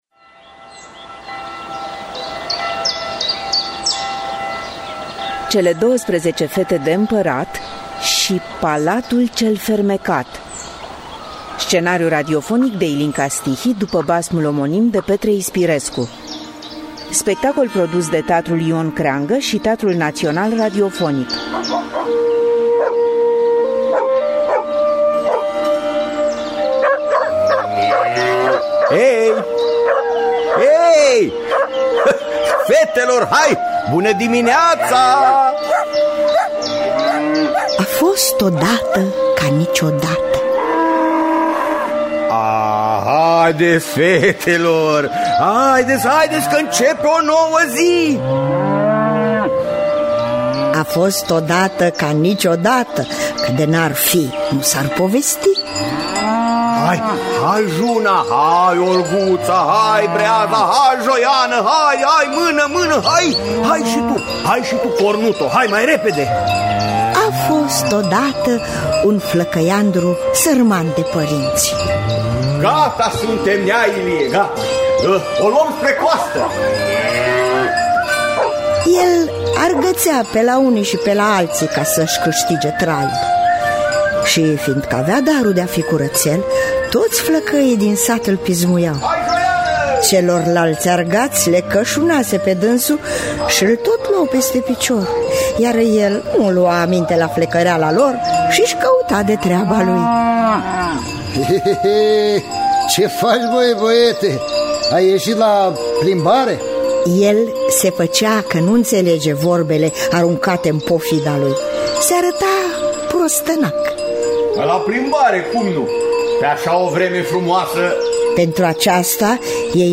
Cele douăsprezece fete de Împărat și palatul cel fermecat de Petre Ispirescu – Teatru Radiofonic Online
Adaptarea radiofonică